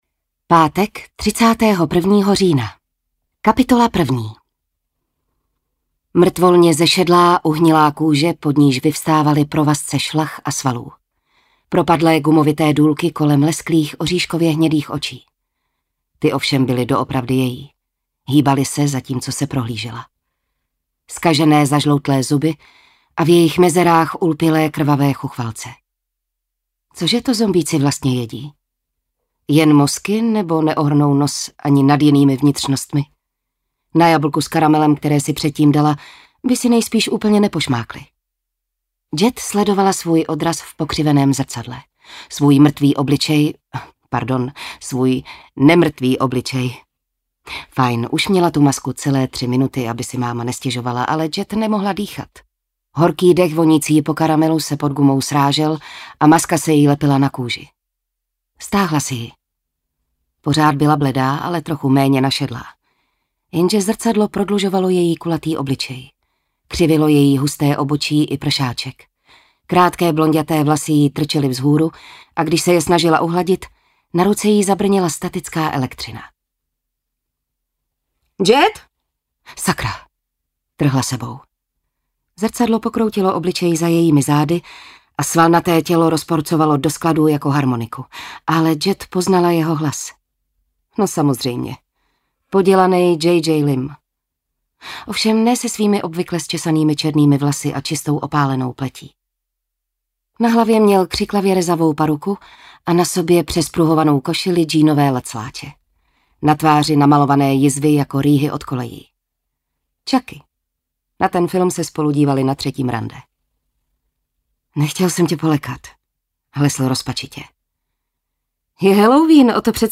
Audiokniha: Ještě nejsem pod zemí
Štúdio Chevaliere